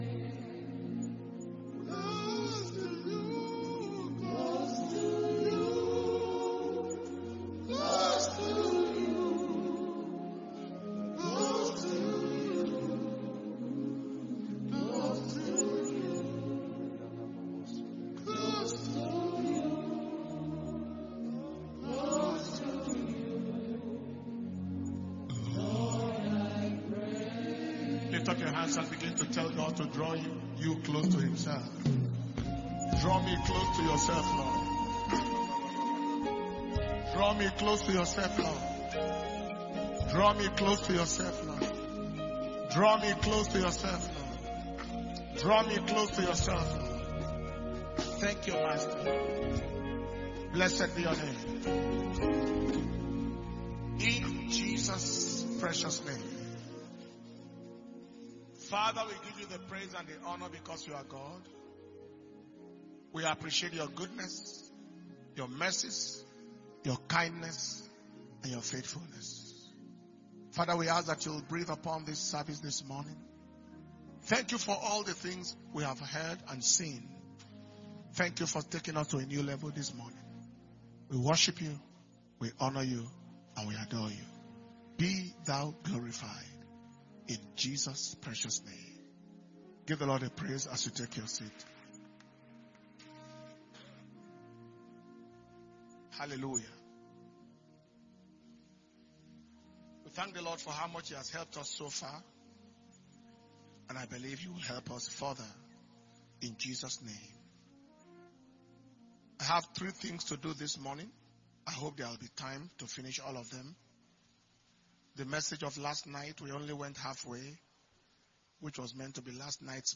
International Minister’s Fire Flaming Conference August 2022 Day 2 Morning Session